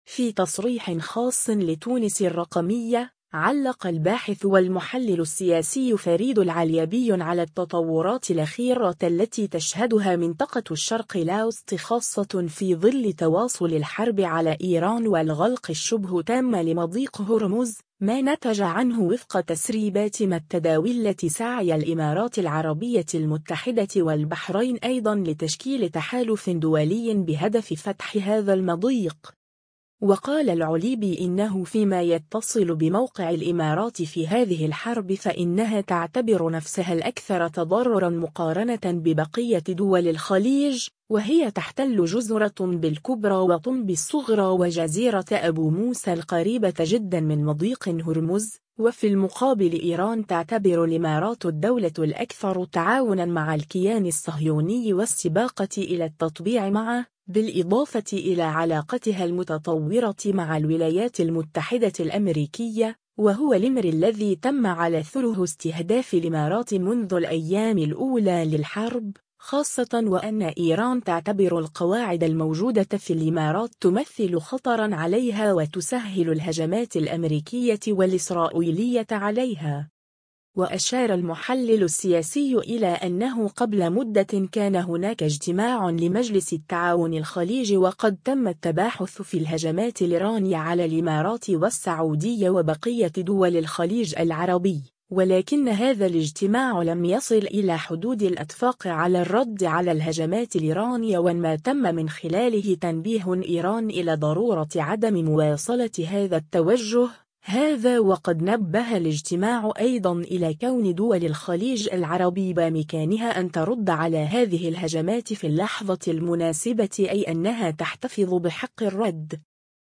في تصريح خاص لتونس الرّقمية